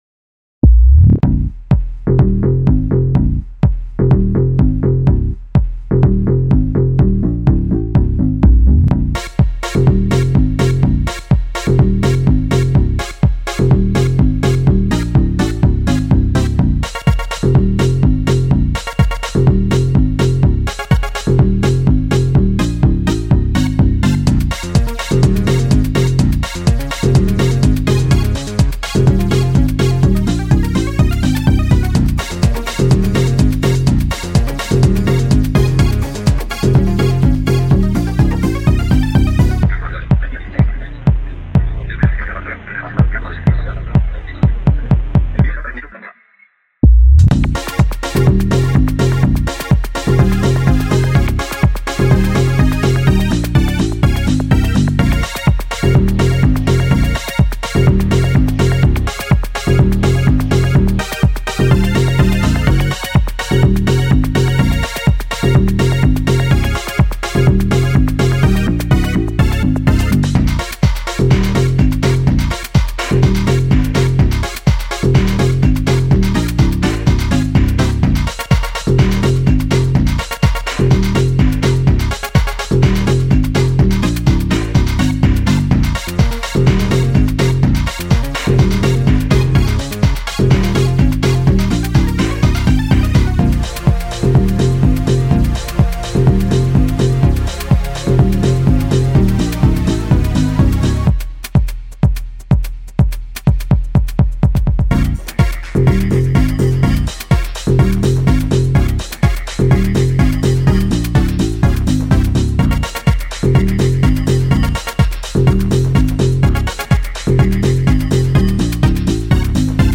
描述：舞蹈和电子音乐|放克
Tag: 合成器 贝司